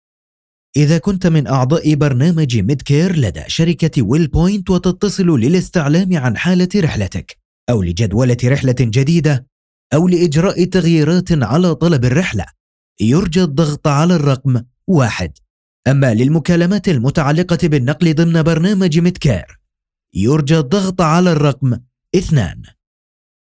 Male
A warm, confident Arabic voice with a clear, polished tone that instantly builds trust. The delivery is natural, steady, and engaging, balancing professionalism with approachability.
Phone Greetings / On Hold
Ivr Prompt